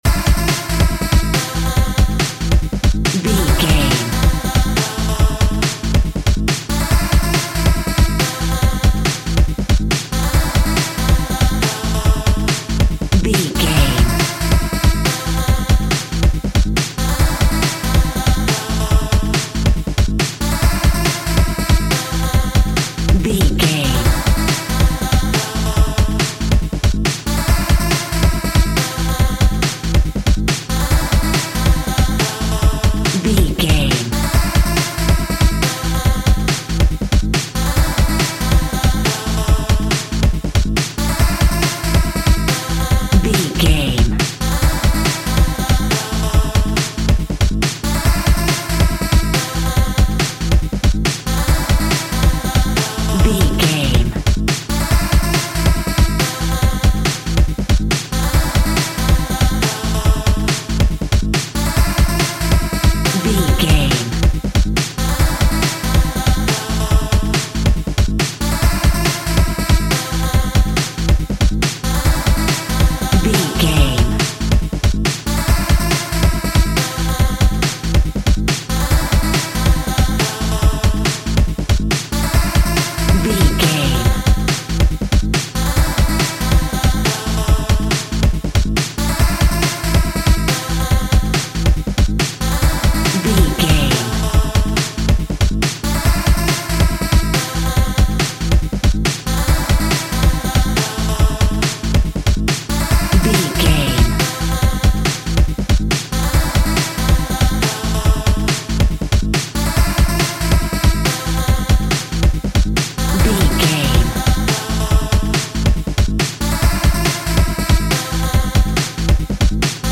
Europe Clubbing Music.
Aeolian/Minor
D
Fast
driving
energetic
futuristic
hypnotic
drum machine
synthesiser
techno
house music
synth leads
synth bass